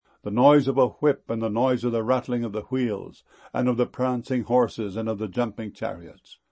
jumping.mp3